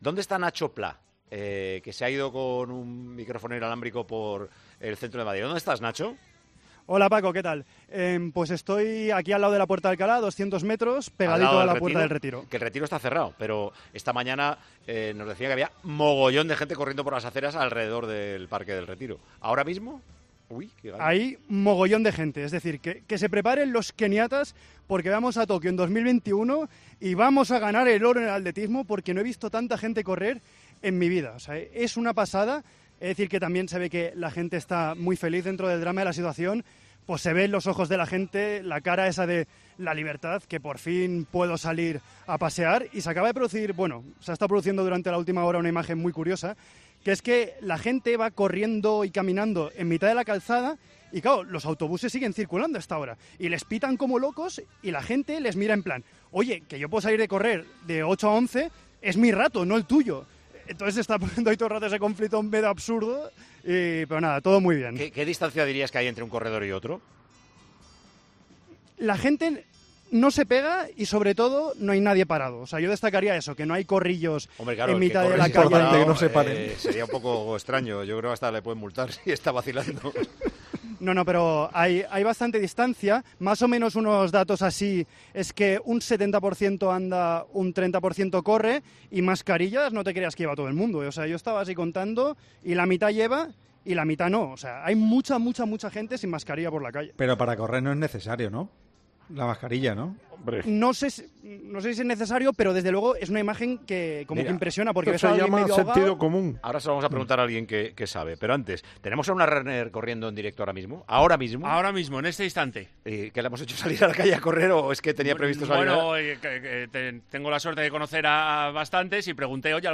Y escuchamos a dos 'runners'.